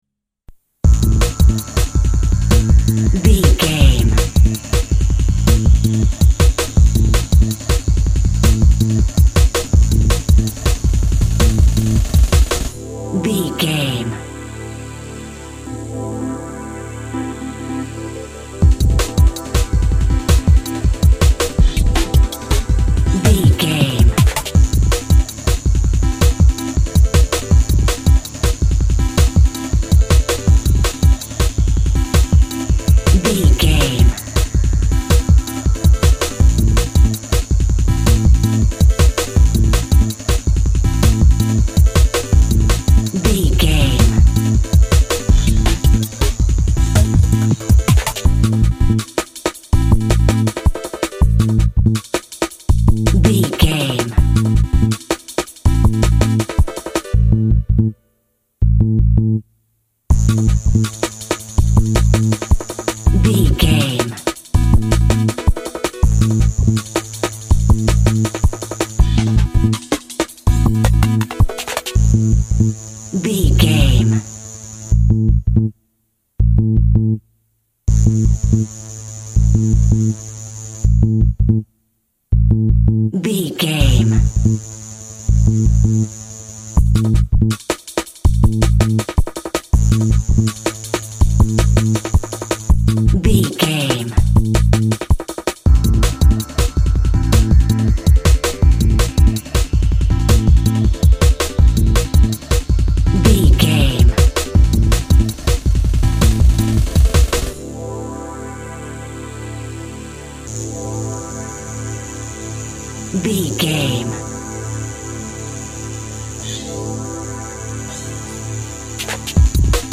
Fast paced
Aeolian/Minor
groovy
uplifting
driving
energetic
repetitive
drum machine
house music
electro
techno
synth lead
synth bass
electronic drums
Synth Pads